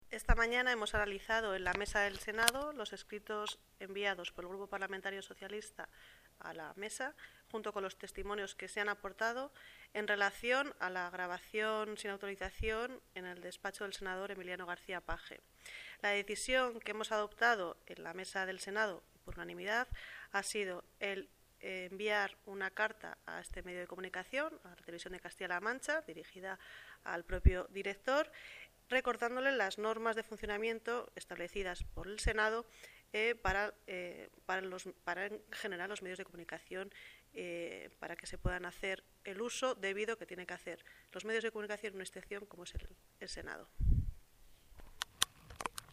Cortes de audio de la rueda de prensa
Audio_Yolanda_Vicente_vicepresidenta_segunda_Senado.mp3